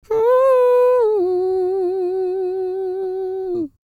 E-CROON P320.wav